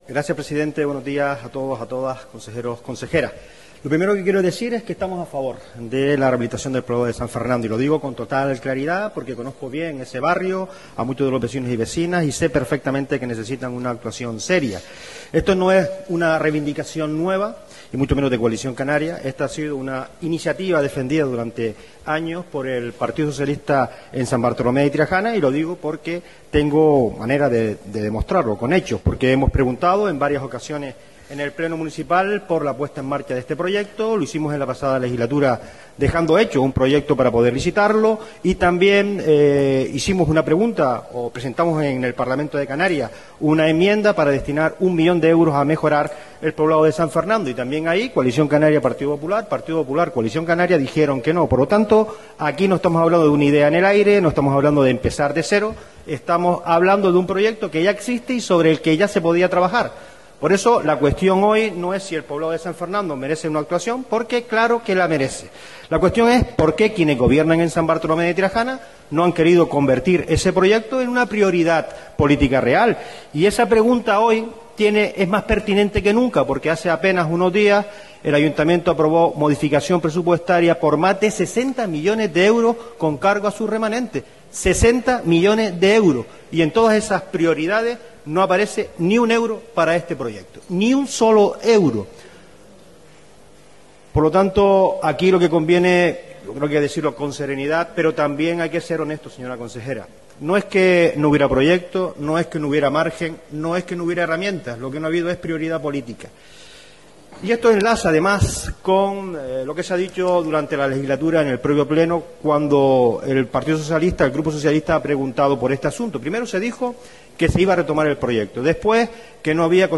El consejero de Turismo del Cabildo de Gran Canaria, Carlos Álamo, criticó este viernes durante el pleno insular la situación del proyecto de recuperación del Poblado Antiguo de San Fernando, en San Bartolomé de Tirajana.